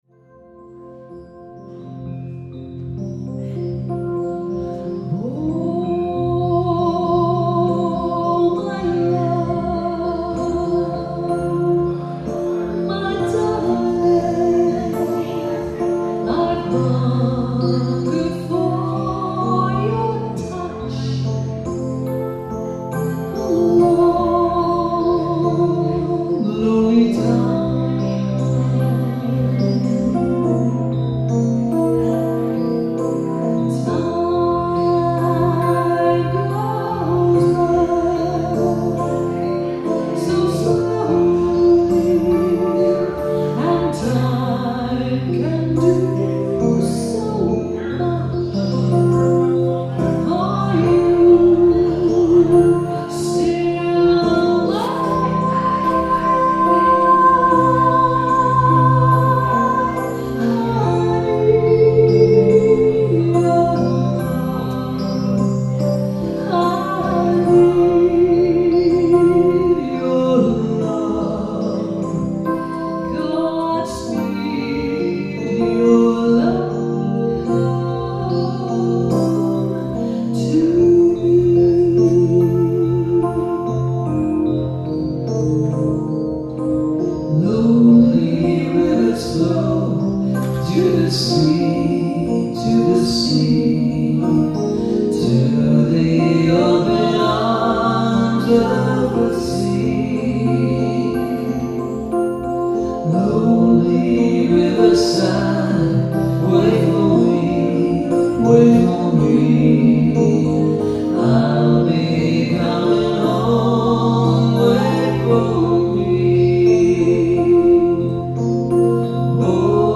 (two or three piece ensemble)
Enjoy warm and contemporary vocal styles
1. Electronic keyboards with male and female vocals
Bridal Dance: